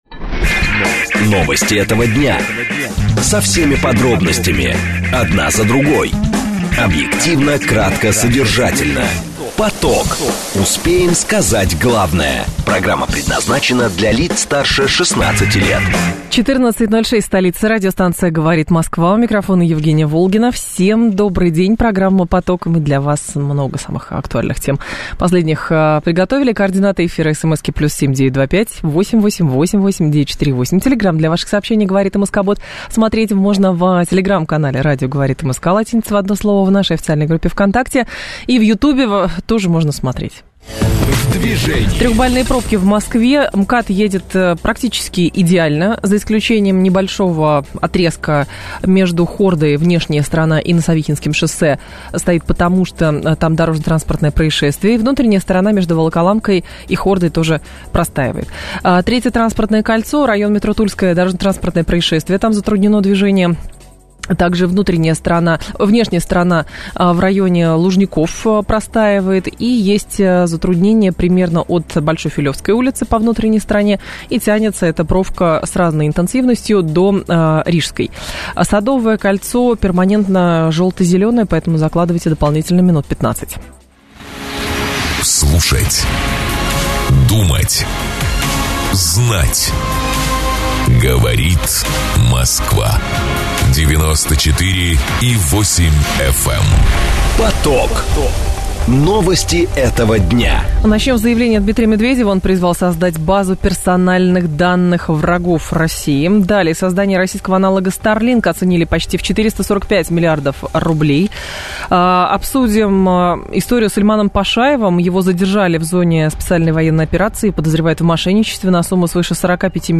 Фрагмент эфира «Говорит Москва» 17.09.2024